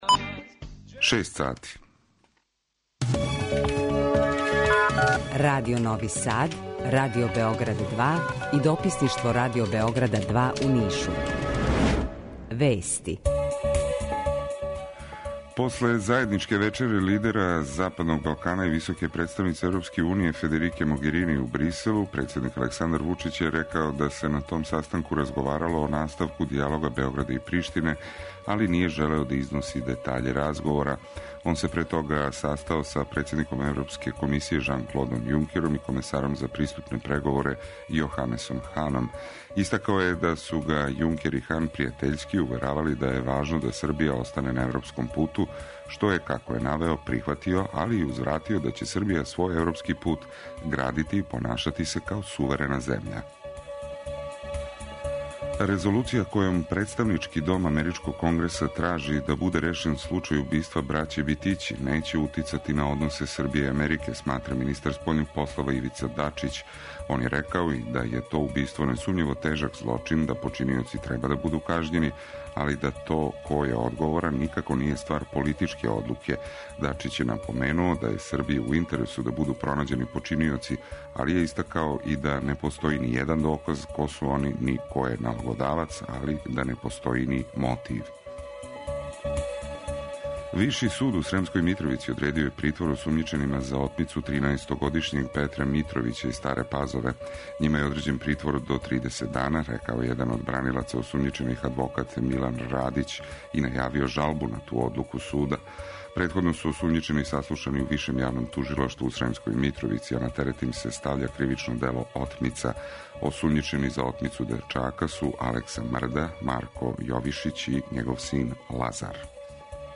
Укључење Радио Грачанице